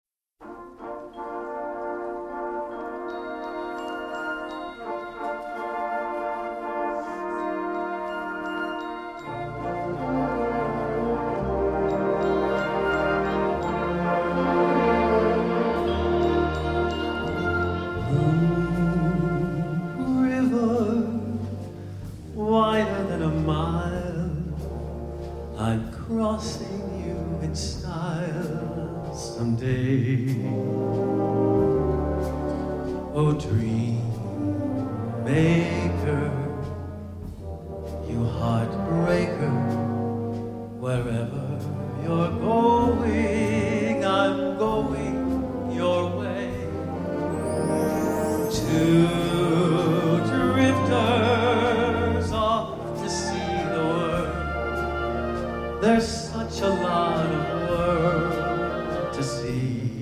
Vocalist